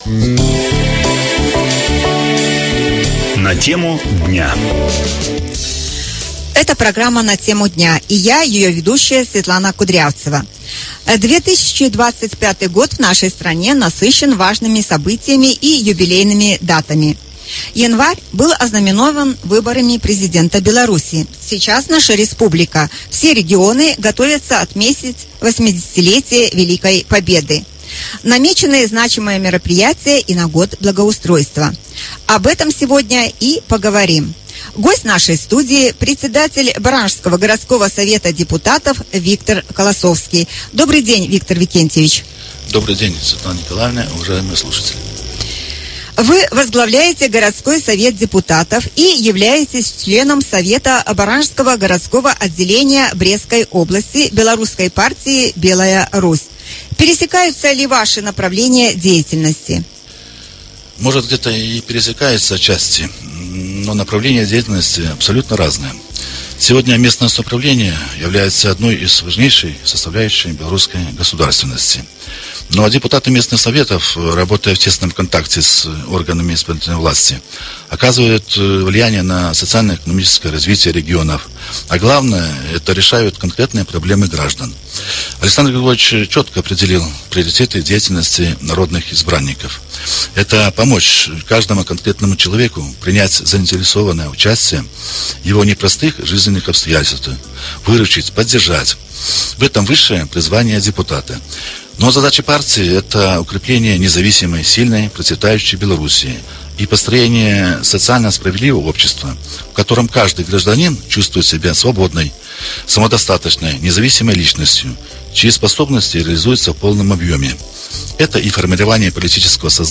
Сейчас наша республика, все регионы готовятся отметить 80-летие Великой Победы, намечены значимые мероприятия и на Год благоустройства. Об этом разговор с председателем Барановичского городского Совета депутатов, членом Совета городского отделения Белорусской партии «Белая Русь» Виктором Колосовским.